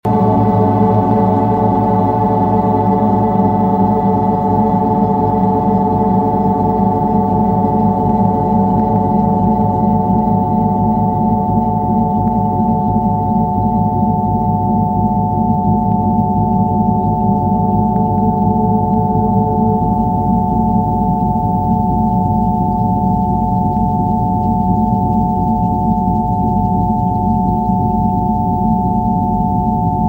Frecuencia 727-800 inflamacion- colitis 🧬🧰 Las frecuencias Rife de 727 Hz y 800 Hz se han utilizado en terapias vibracionales para ayudar al cuerpo a liberarse de bacterias y parásitos, dos causas comunes de inflamación crónica. Este audio combina ambas frecuencias físicas para brindar un apoyo energético profundo, ideal durante procesos de limpieza o desintoxicación.